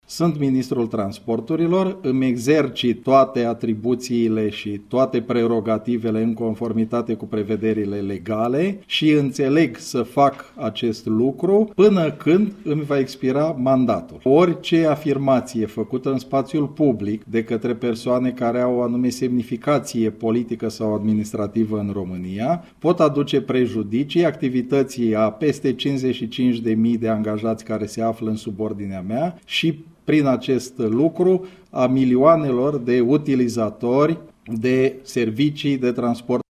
Ministrul demisionar al Transporturilor, Lucian Şova, a susţinut, astăzi, într-o conferinţă de presă, că îsi exercită, în continuare, toate atribuţiile şi prerogativele legale până la expirarea mandatului. El a făcut un apel la responsabilitate în spaţiul public, după declaraţiile care au apărut privind posibilul blocaj din minister: